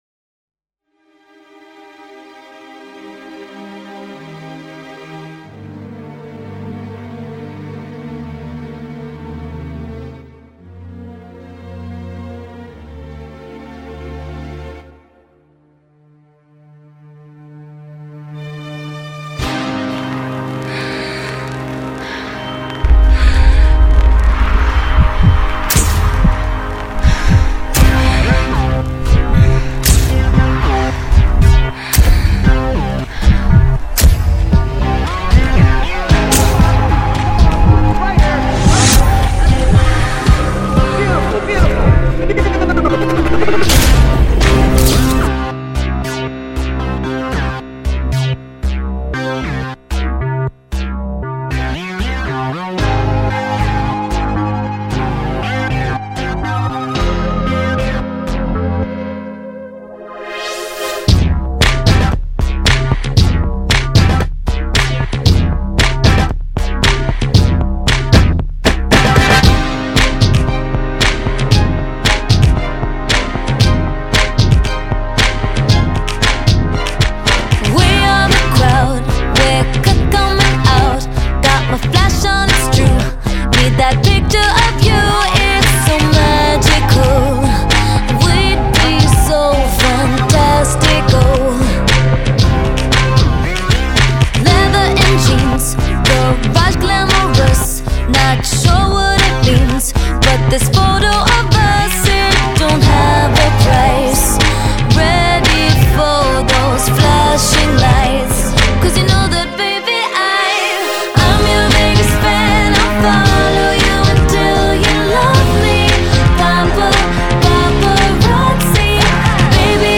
Sound: Stereo